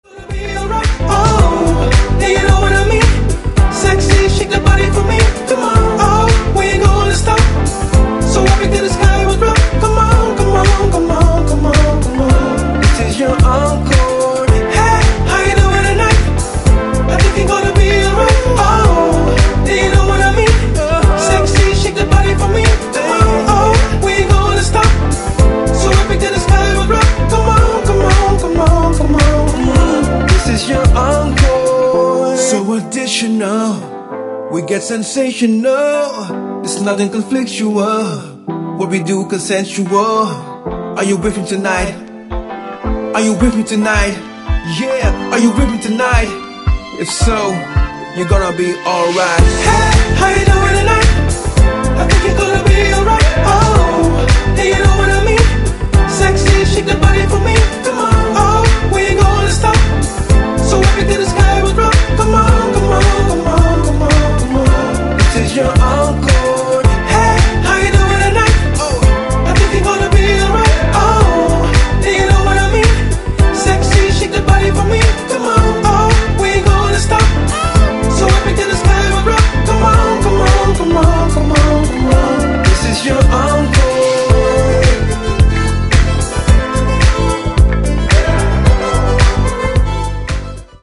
ジャンル(スタイル) NU DISCO / DISCO / HOUSE